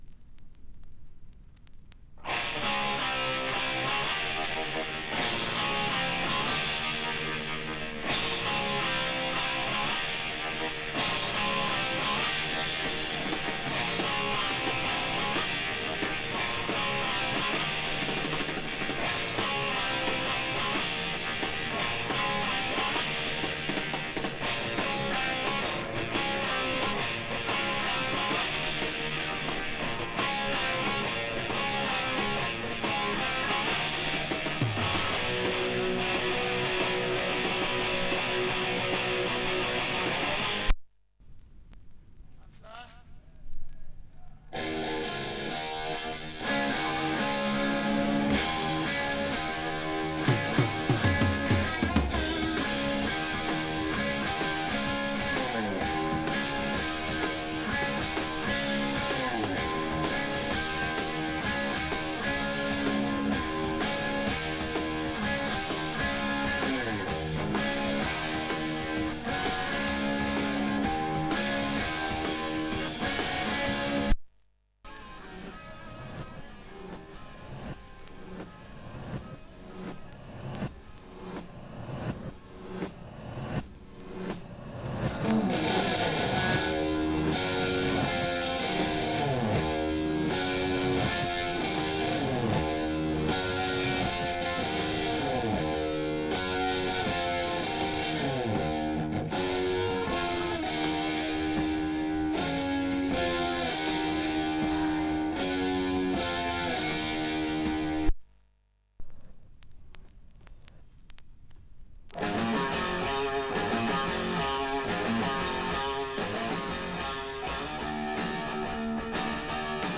Жанр: HEAVYMETAL